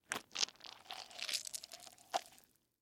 Звук: извлекаем внутренности дыни